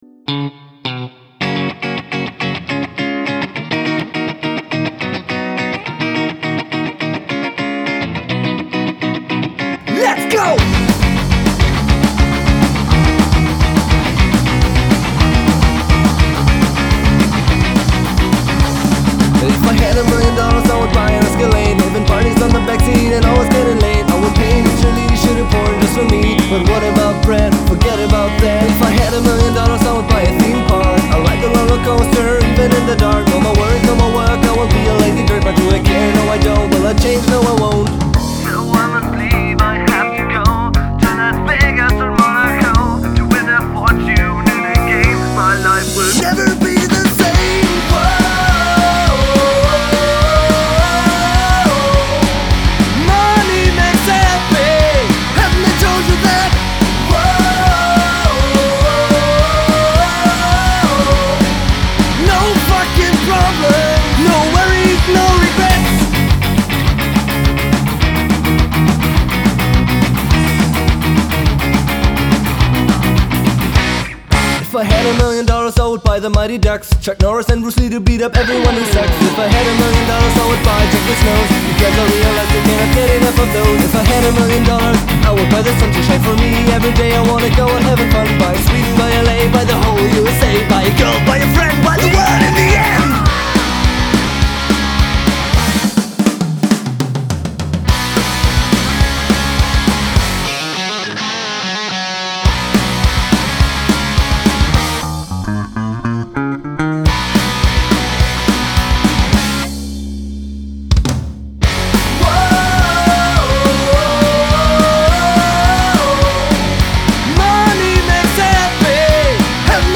Fun-Punk-Rock